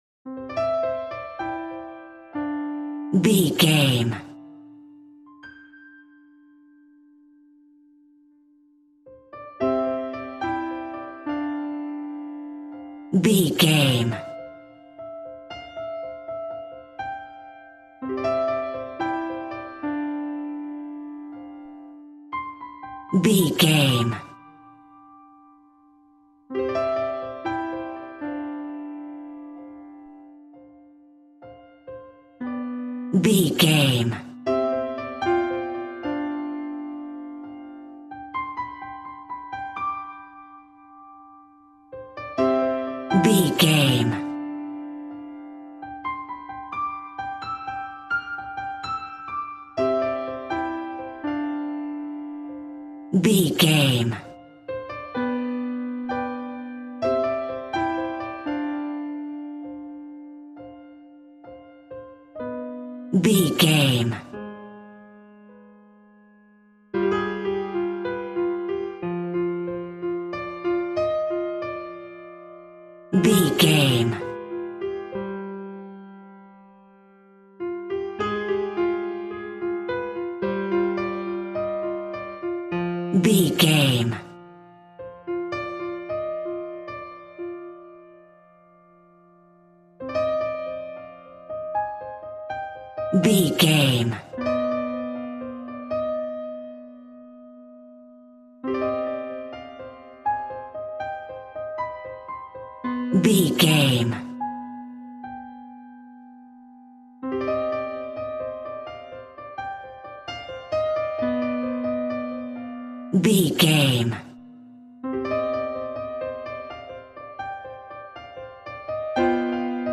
Ionian/Major
Slow
tension
dark
dramatic
foreboding
haunting
suspense
piano
creepy
synth
ambience
pads